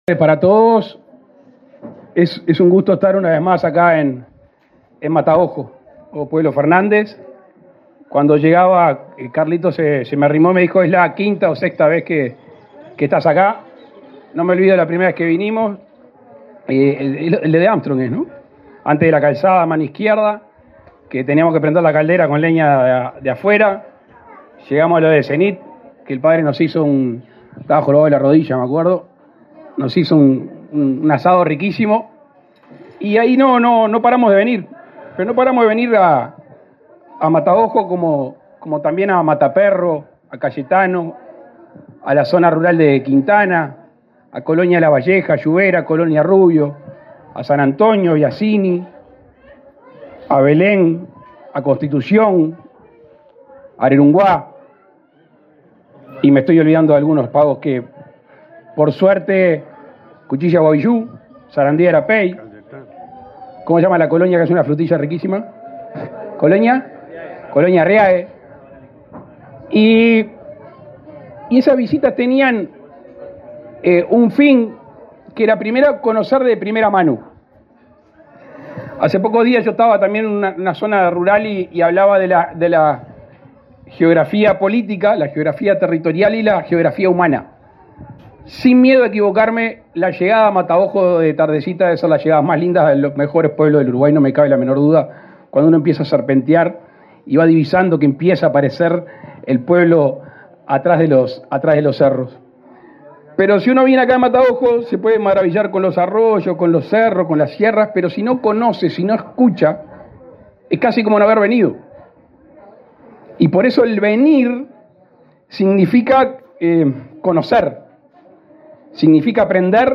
Palabras del presidente de la República, Luis Lacalle Pou
Palabras del presidente de la República, Luis Lacalle Pou 16/08/2023 Compartir Facebook X Copiar enlace WhatsApp LinkedIn El presidente de la República, Luis Lacalle Pou, participó, este 16 de agosto, en el apagado del último generador autónomo del país, ubicado en el pueblo Fernández, en el departamento de Salto, localidad que se incorporó al sistema eléctrico de UTE.